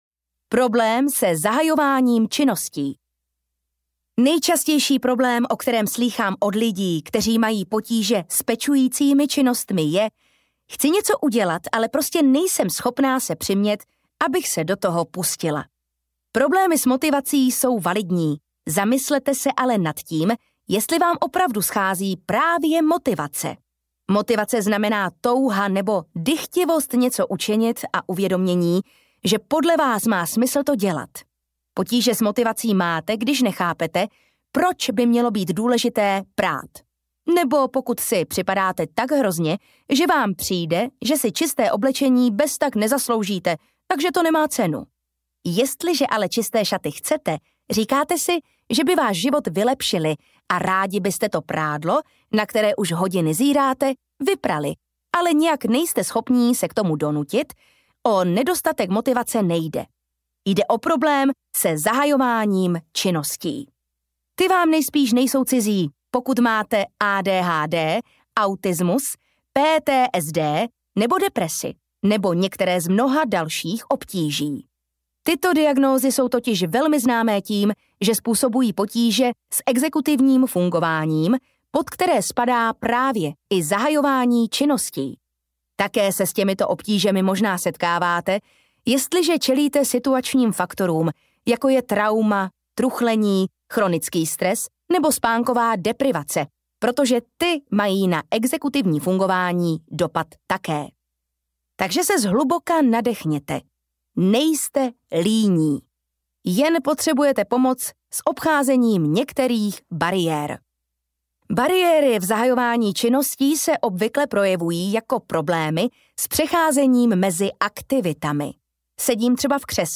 Neutopte se v nepořádku audiokniha
Ukázka z knihy